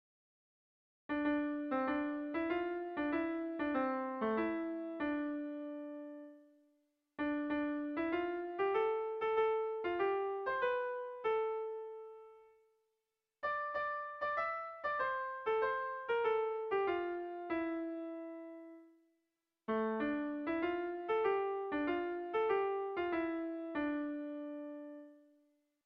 Melodías de bertsos - Ver ficha   Más información sobre esta sección
Tragikoa
Zortziko txikia (hg) / Lau puntuko txikia (ip)
ABDE